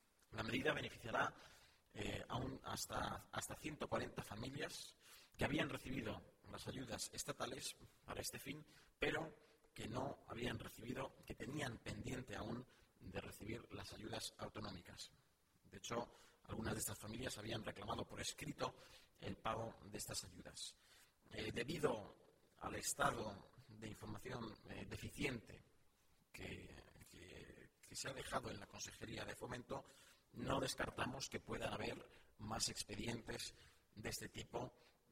El portavoz del Gobierno regional, Nacho Hernando, sobre ayudas para adquisición de viviendas: